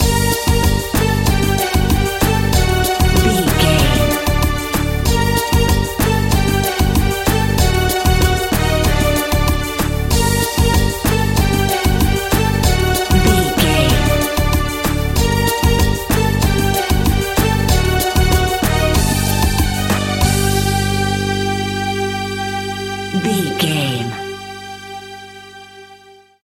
Aeolian/Minor
percussion
congas
kora
djembe